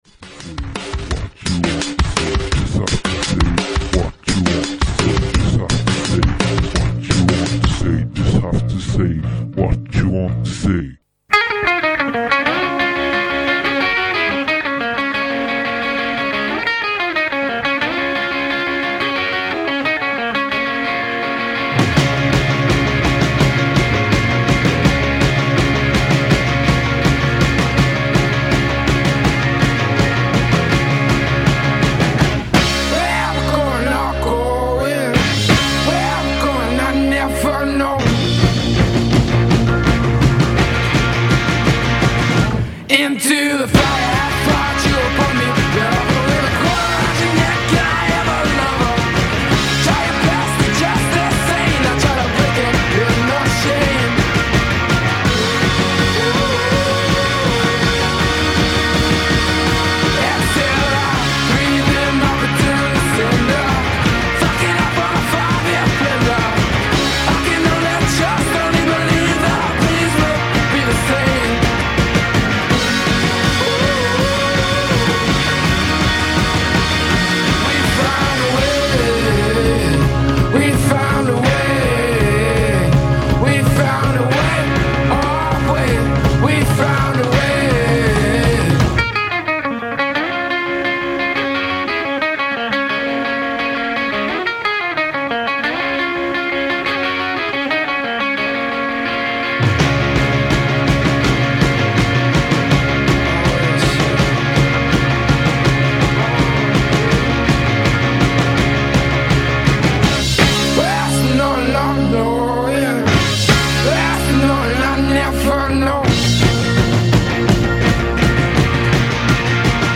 Extrait de l'Emission "Zack and Judy" sur Radio Saint Ferréol
Genres : Blues rock, Garage rock, Punk blues ...